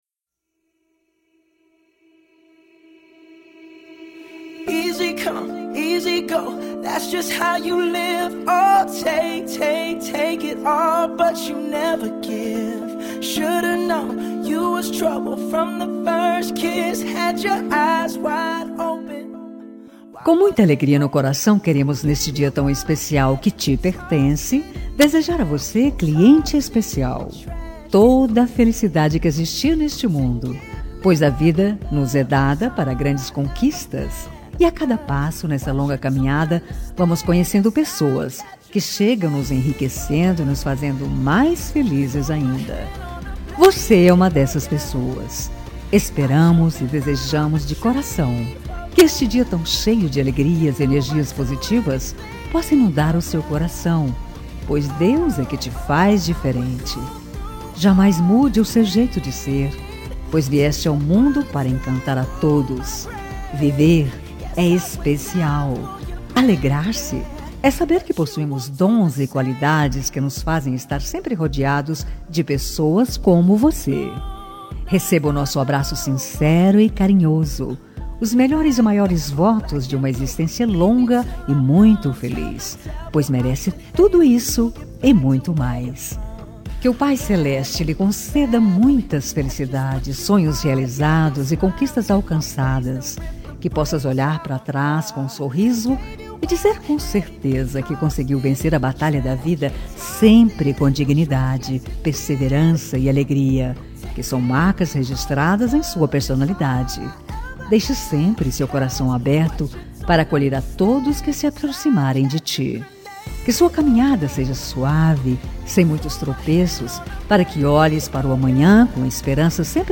Voz Feminina
Código: 70402 – Música: Grenade – Artista: Brun Mars
32-aniv.cliente-fem-Brun-Mars-Grenade.mp3